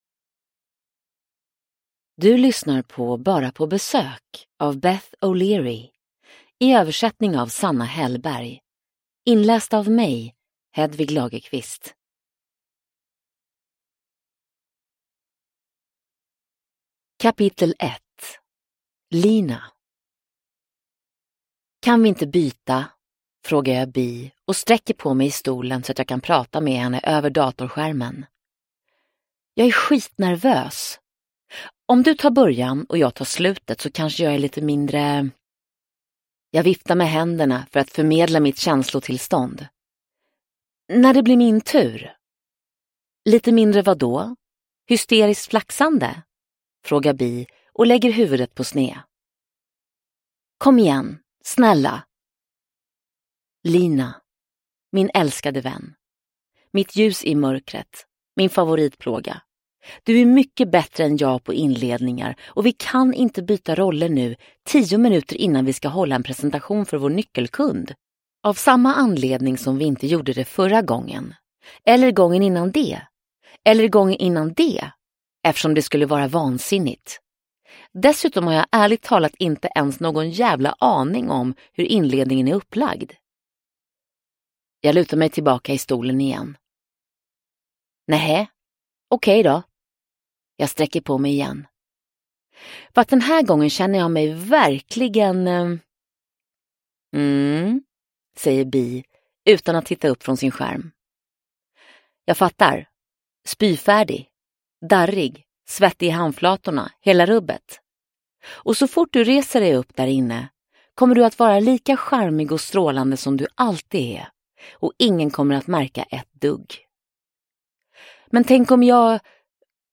Bara på besök – Ljudbok – Laddas ner
Uppläsare: Frida Hallgren